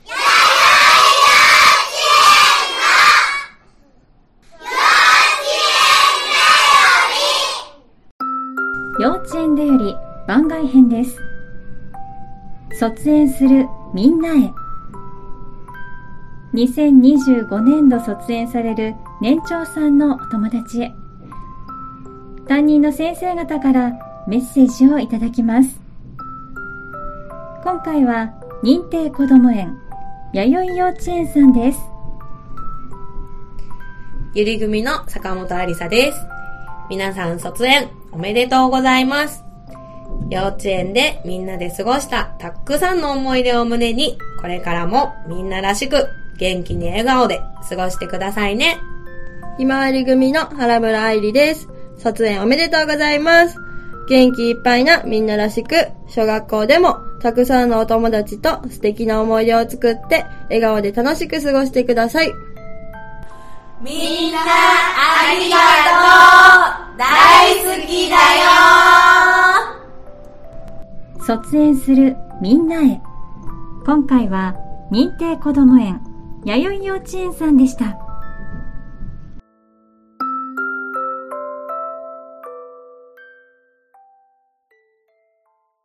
2025年度卒園する年長さんへ、先生方からのメッセージをお届けします！